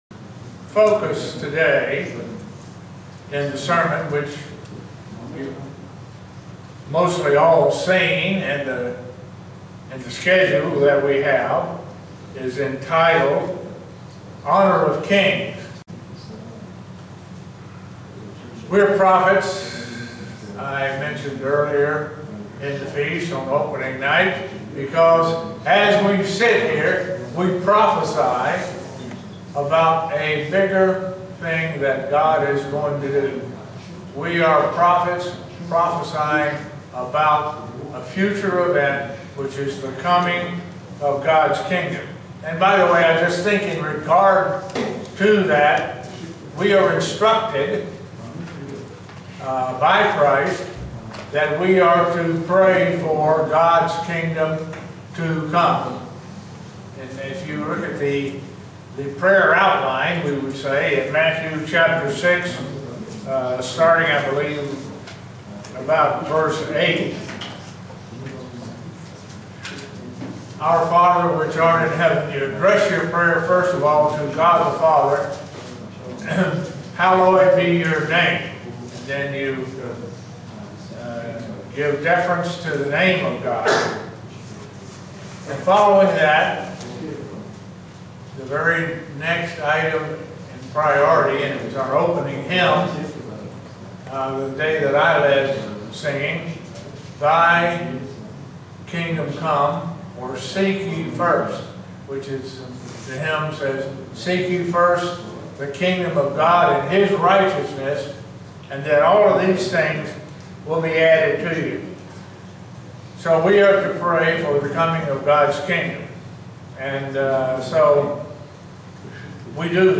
Print We are being prepaired to rule as Kings in Gods' Kingdom. sermon Studying the bible?